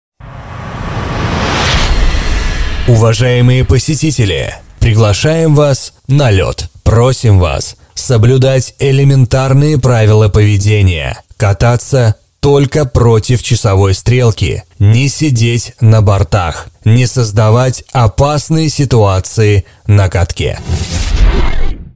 мой голос... объявление на катке..